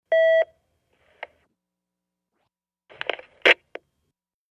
Intercom beep & in - line hang up